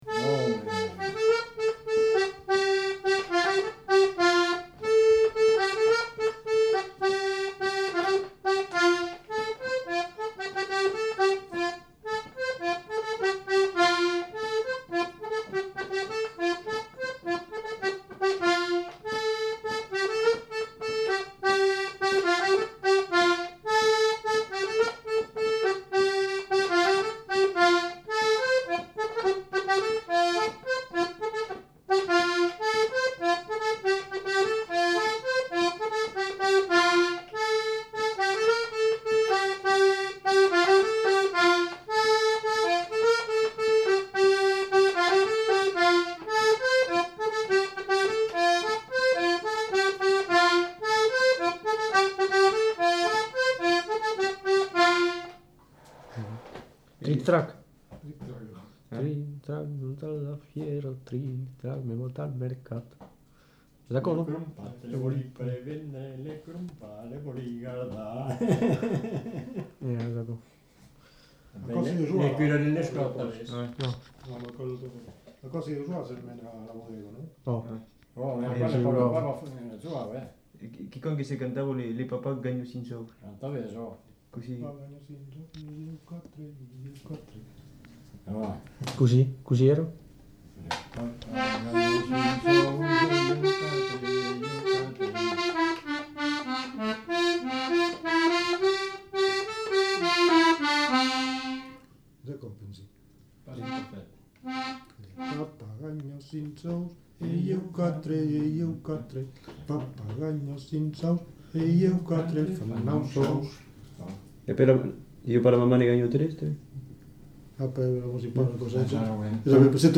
Aire culturelle : Lauragais
Lieu : La Pomarède
Genre : morceau instrumental
Instrument de musique : accordéon diatonique
Danse : polka piquée
Notes consultables : Suivi d'un fragment de chant (Mon papà ganha cinc sòus).